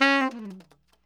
TNR SHFL C#4.wav